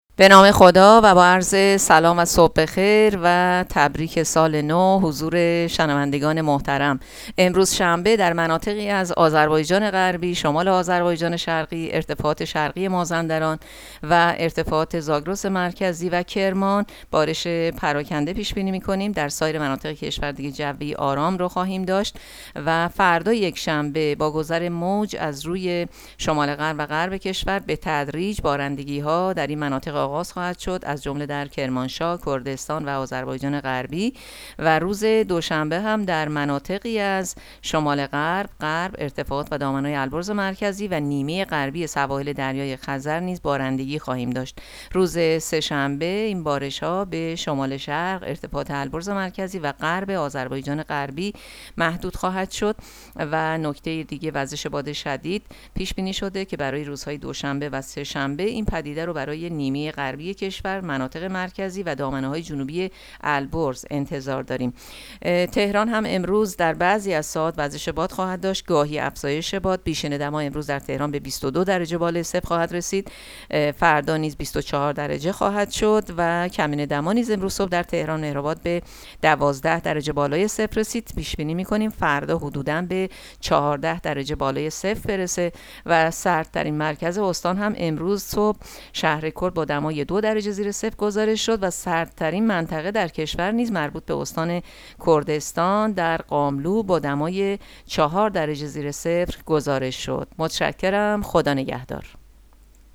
گزارش رادیو اینترنتی پایگاه‌ خبری از آخرین وضعیت آب‌وهوای ۹ فروردین؛